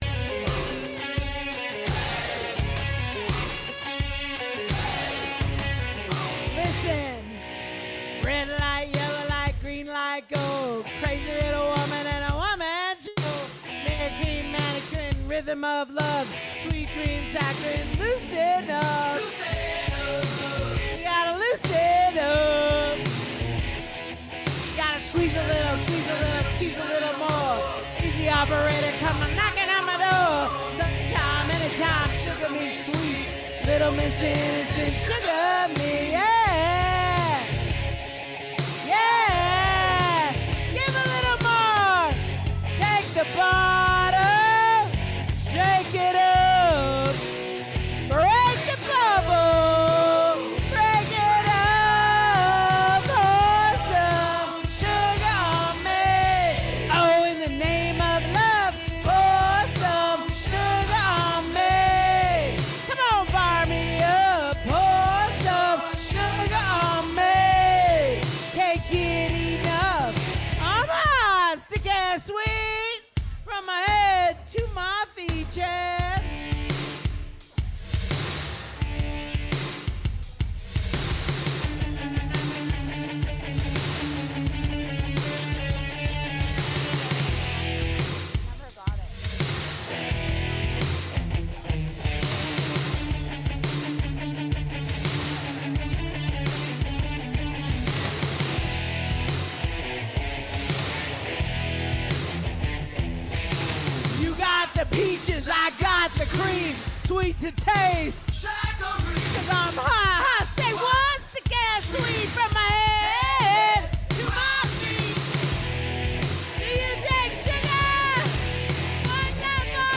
Live Wednesday through Saturday 9-1 with the Metro Best Karaoke with Mile High Karaoke on 04-Oct-25-00:06:07
Mile High Karaoke Live Wednesday through Saturday 9-1 Broadways Shot Spot